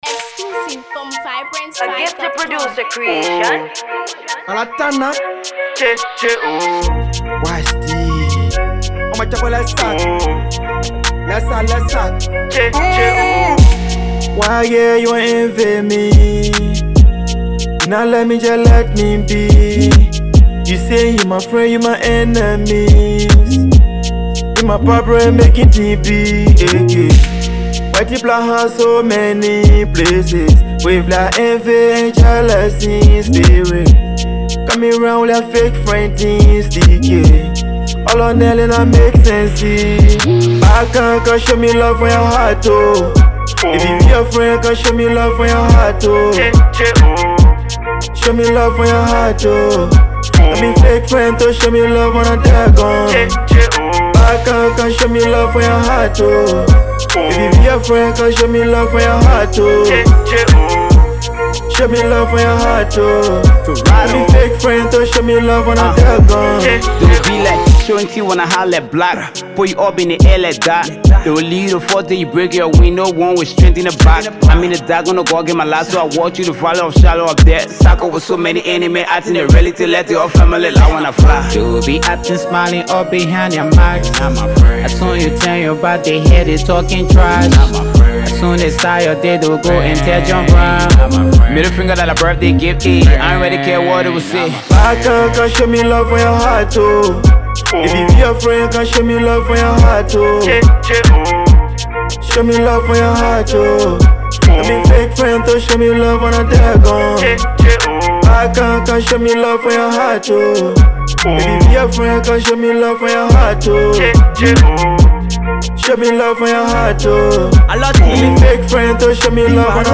drill banger